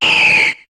Cri d'Altaria dans Pokémon HOME.